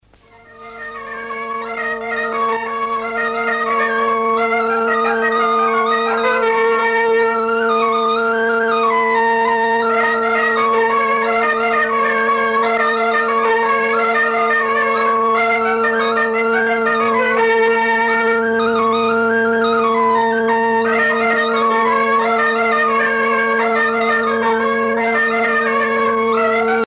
Ronde (sonnée)
Motte (La)
danse : ronde
Pièce musicale éditée